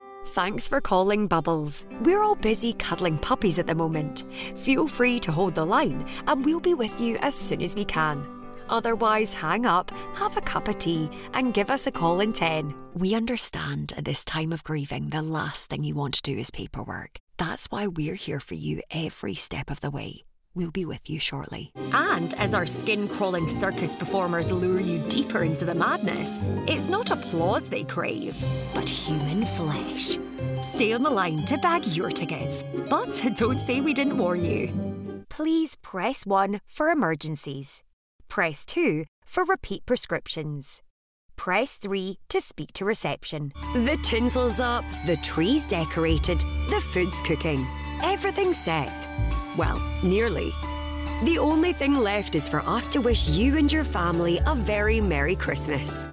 Inglés (escocés)
IVR
Estudio totalmente insonorizado con tratamiento acústico.
Contralto